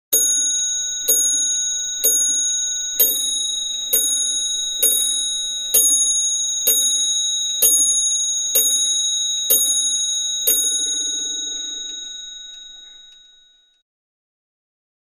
Clock 7, 1850, Strikes 12; Aristocrat's Mantelpiece Clock, Named Mysterious, Strikes 12 O'clock With Beautiful Small Glass Bell